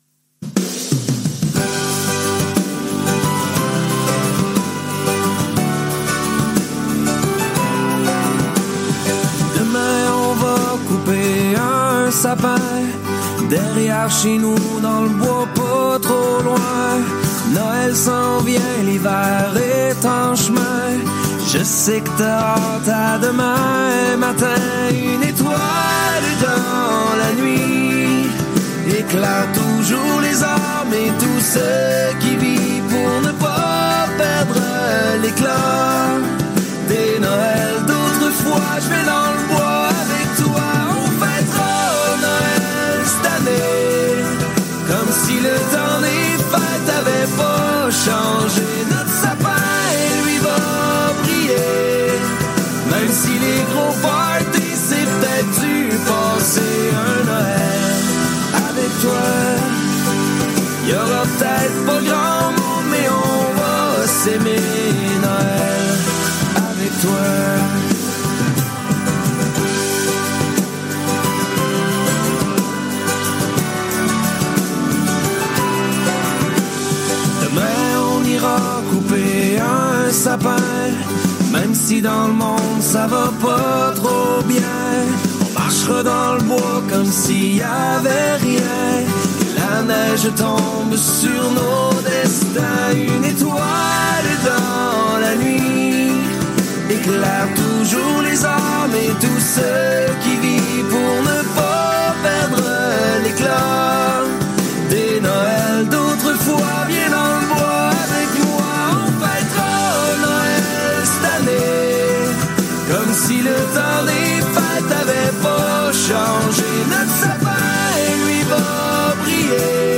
On écoute des tubes mais aussi des reprises et des titres moins connus, des années 60’s à nos jours, avec un jeu musical permettant de faire fonctionner notre mémoire musicale car le nom des interprêtes n’est cité qu’après la diffusion des chansons.